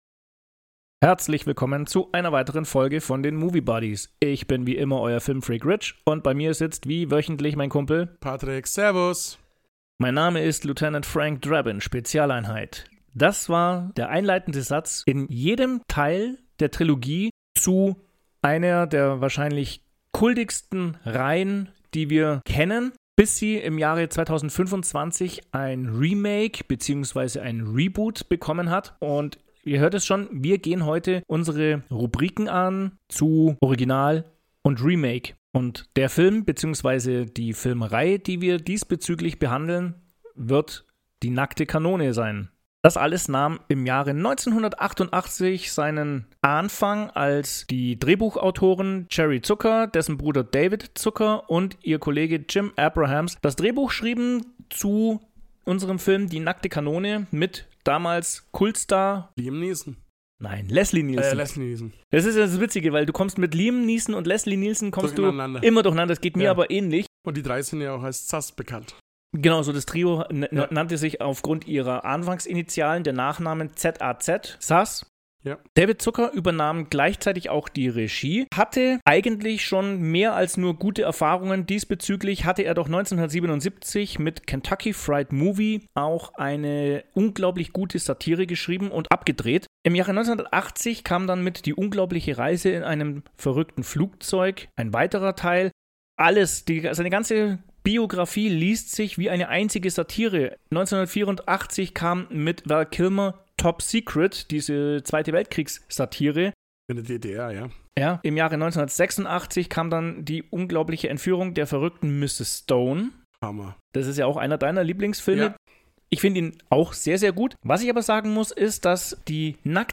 Erlebt eine amüsante Unterhaltung über die Original-Trilogie und die Reaktionen auf die Ankündigung einer Neuinterpretation oder wie sich der Humor hinsichtlich der gesellschaftlichen Entwicklung verändert hat.